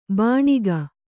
♪ bāṇiga